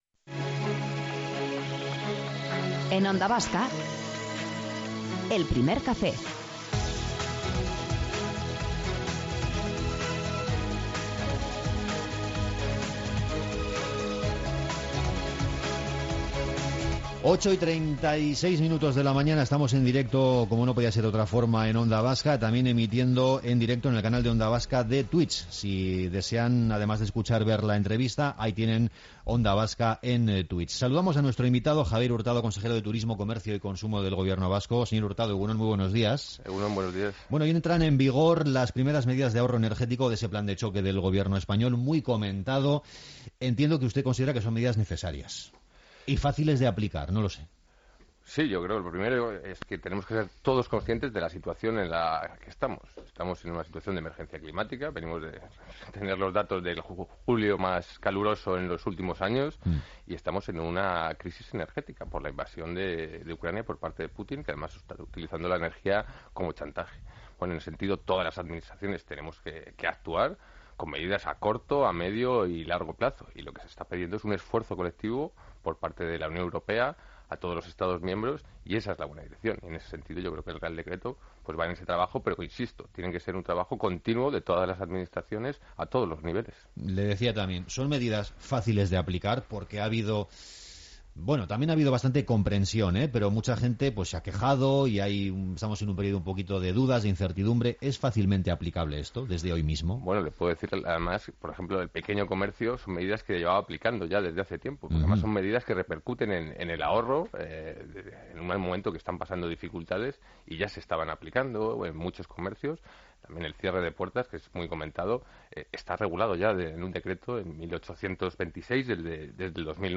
Según ha destacado el consejero de Turismo y Comercio, Javier Hurtado, entrevistado en Onda Vasca, en los primeros 6 meses del año el número de visitantes ha crecido un 2,5 % en la Comunidad Autónoma Vasca.